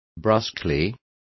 Complete with pronunciation of the translation of brusquely.